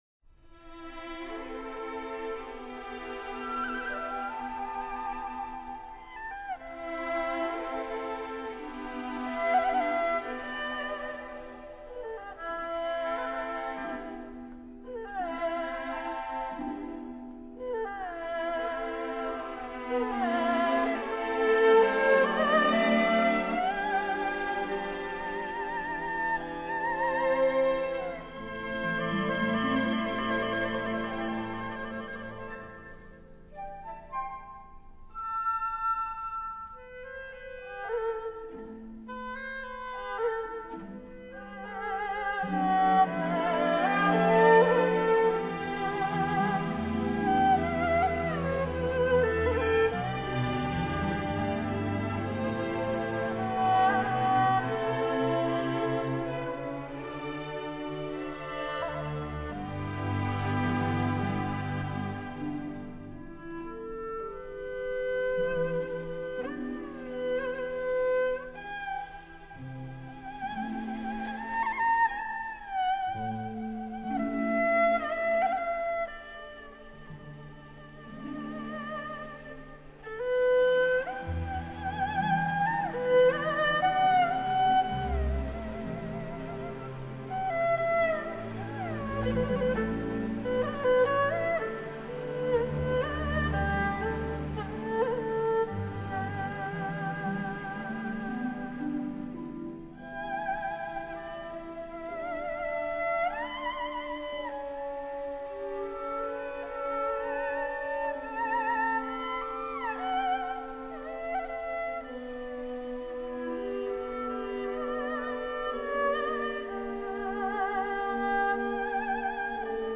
录音地点∕北京中央人民广播电台音乐厅
亚洲顶级中国人民广播电台一号录音棚录制